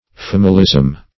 familism - definition of familism - synonyms, pronunciation, spelling from Free Dictionary
Familism \Fam"i*lism\, n.